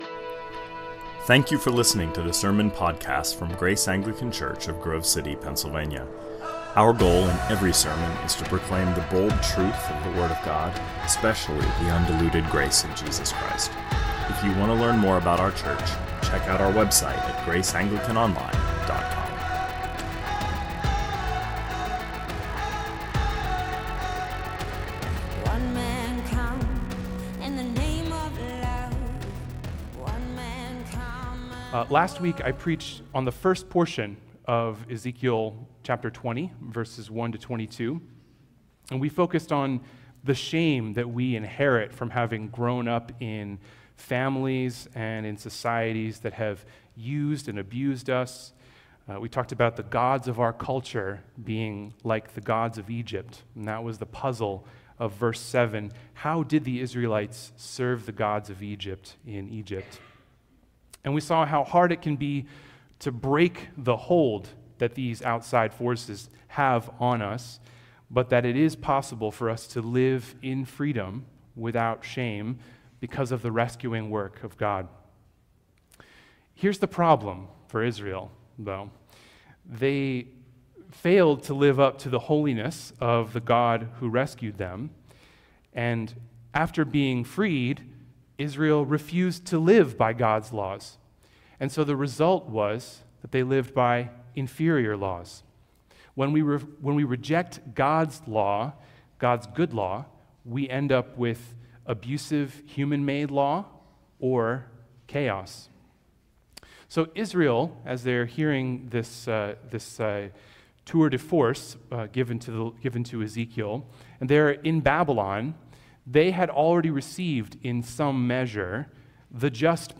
2024 Sermons Ezekiel and the Idols PART 2 -Ezekiel 21 Play Episode Pause Episode Mute/Unmute Episode Rewind 10 Seconds 1x Fast Forward 30 seconds 00:00 / 26:19 Subscribe Share RSS Feed Share Link Embed